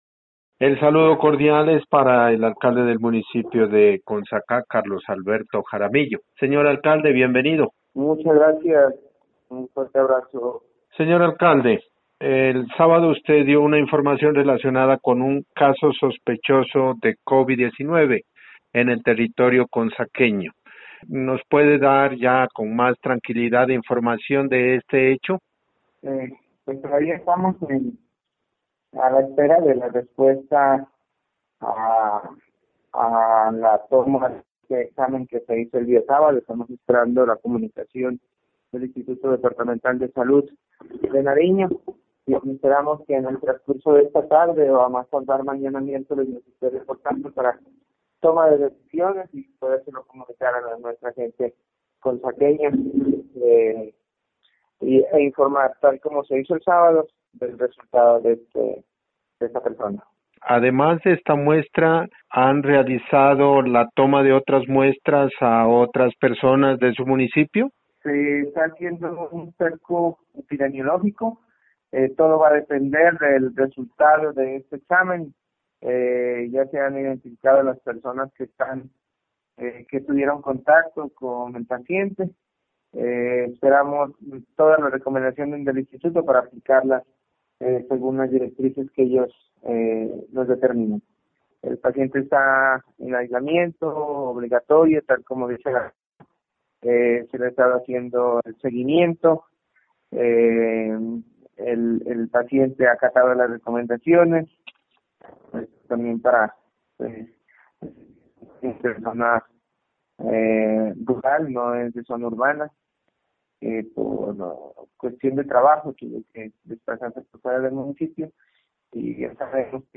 Entrevista con el alcalde de Consacá Carlos Alberto Jaramillo:
Situación-actual-de-Consacá-Alcalde-Carlos-Alberto-Jaramillo.mp3